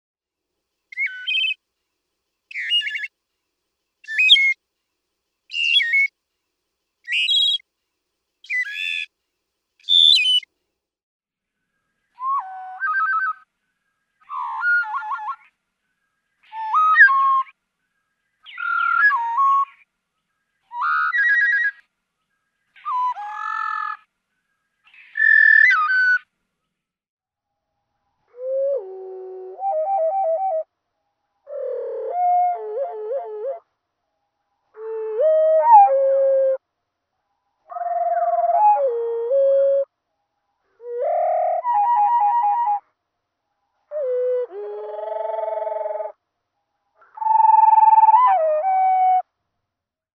♫441. This male sings seven different preludes, played here at normal, half, and quarter speed. (0:50)
441_Wood_Thrush.mp3